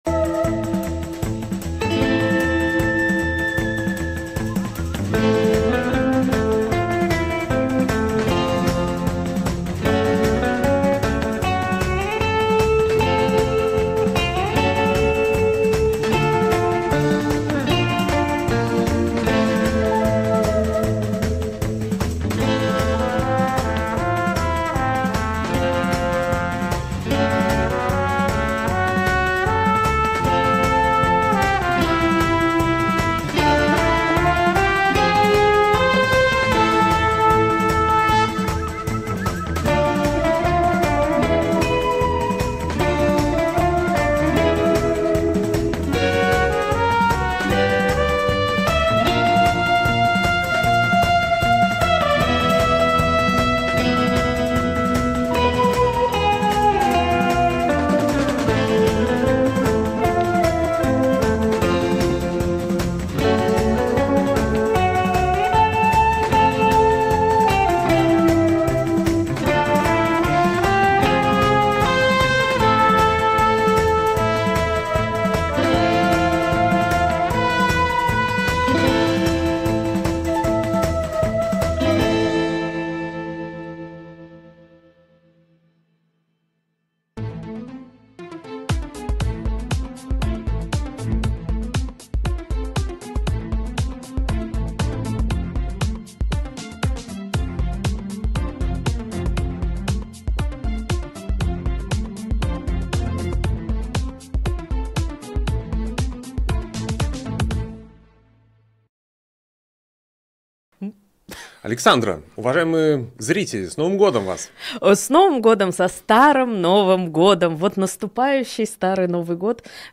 Обсудим с экспертами в прямом эфире все главные новости.